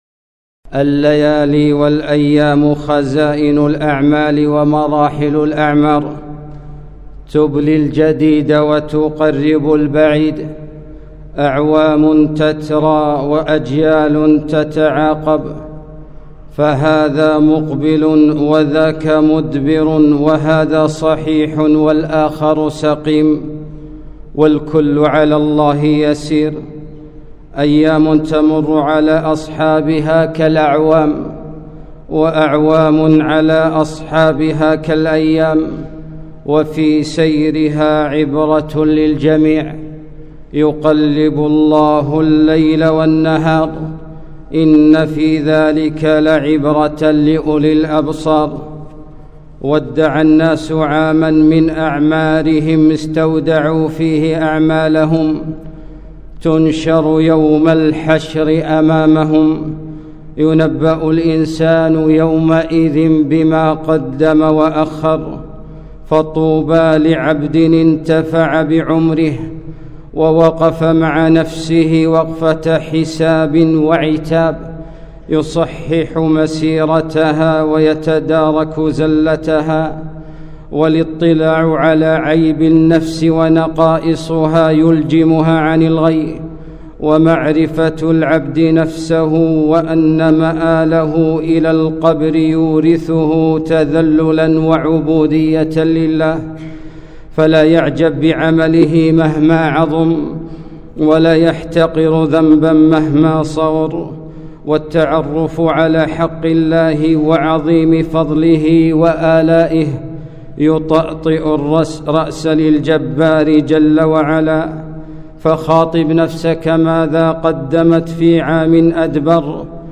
خطبة - يقلب الله الليل والنهار